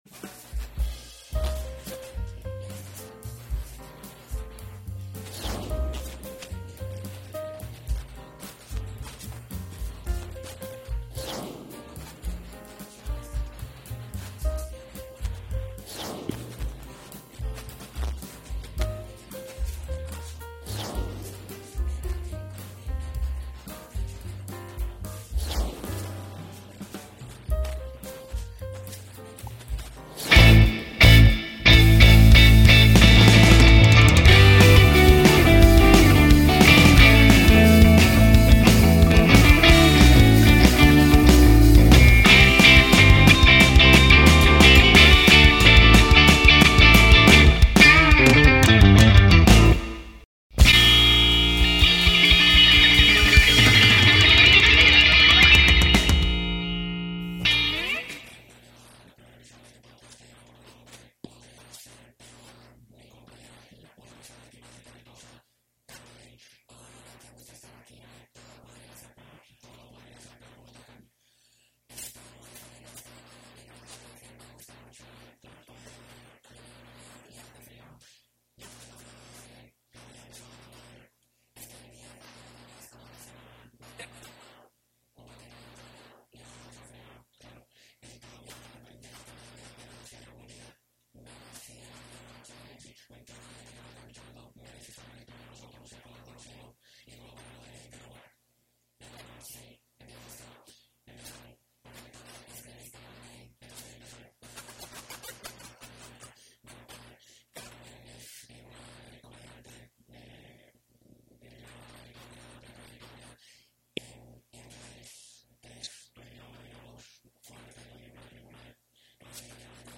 EPI 01 Bienvenidos al primer episodio de "Podría ser peor" conducido por la comediante estadounidense-española Carmen Lynch y el comediante y conductor de radio y televisión venezolano Luis Chataing.